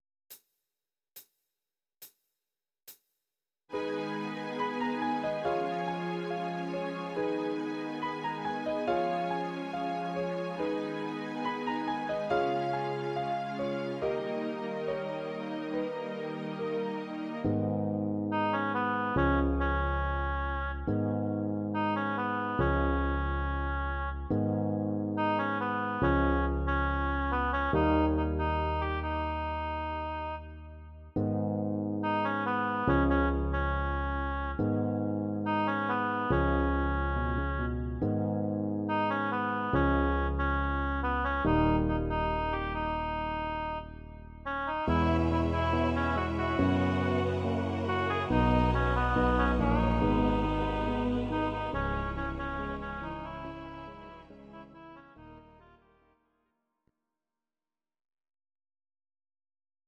These are MP3 versions of our MIDI file catalogue.
Please note: no vocals and no karaoke included.
Your-Mix: Pop (21641)